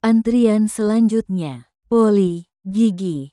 tts